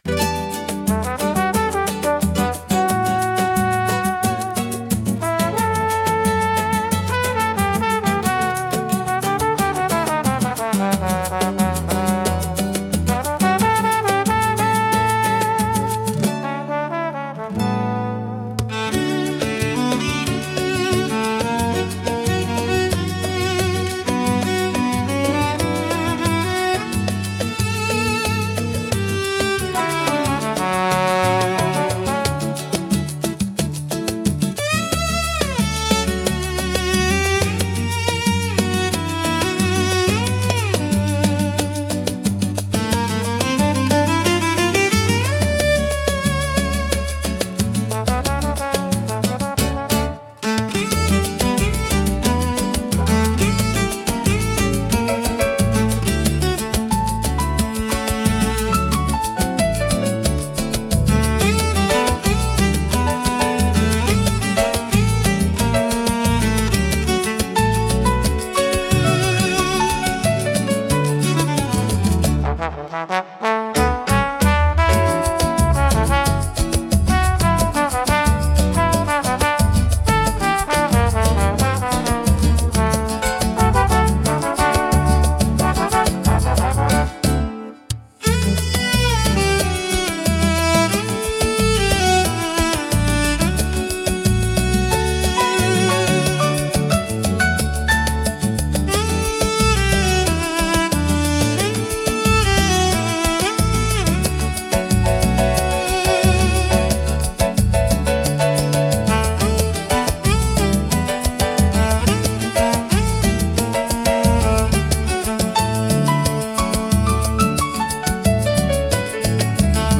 música e arranjo: IA) Instrumental 8